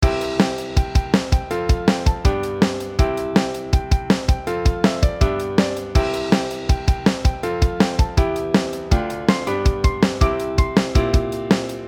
C F G。Aメロを想定